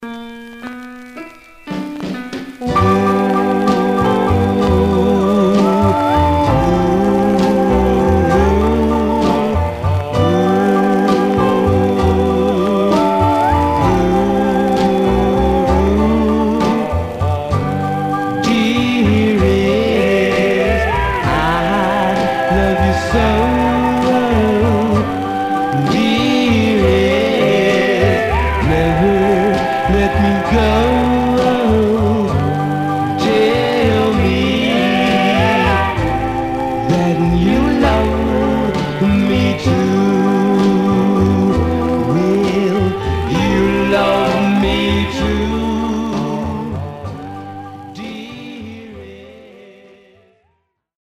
Surface noise/wear
Mono
Male Black Groups